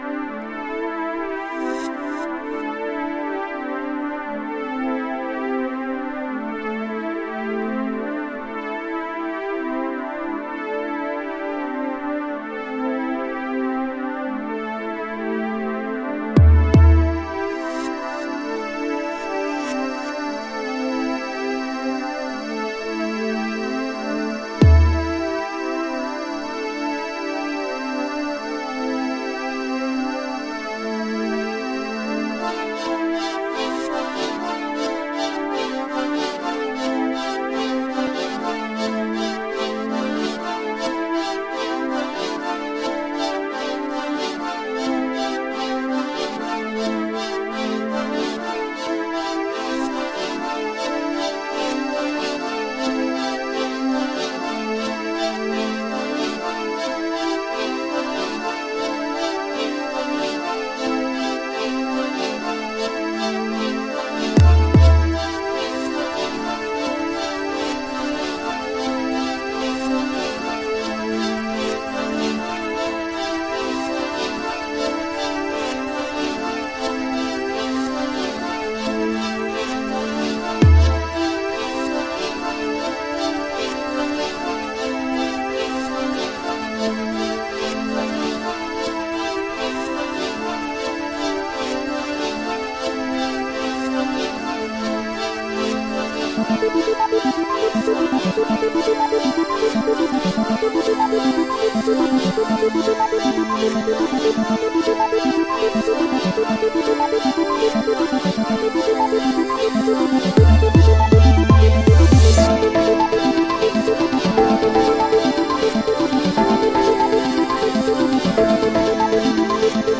デトロイトテクノへの愛情と憧憬が詰まった3トラック